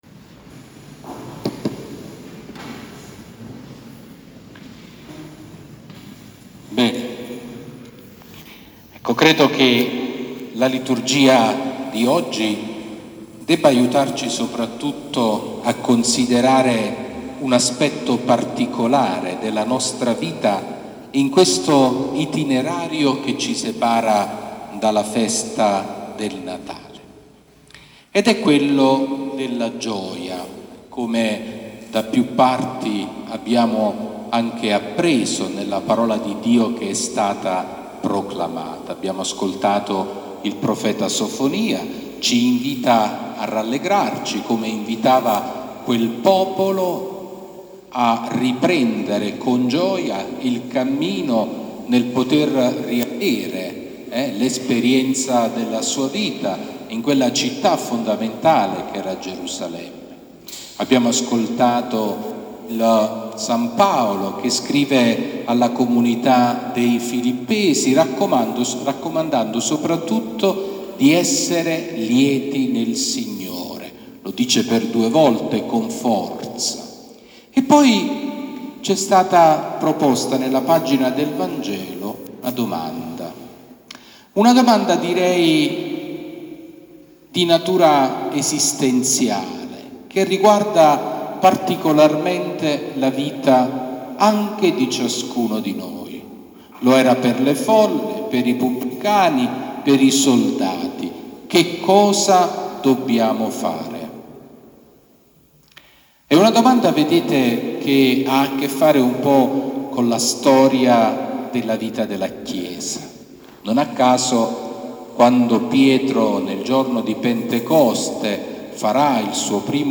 Omelia di Mons. Sabino Iannuzzi durante la Santa Messa per il ritiro di Avvento per giovani e adolescenti
L'omelia del Vescovo Sabino durante la Santa Messa per il ritiro di Avvento per giovani e adolescenti presso la Parrocchia Santi Francesco e Chiara di Castellaneta.